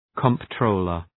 Προφορά
{kən’trəʋlər}
comptroller.mp3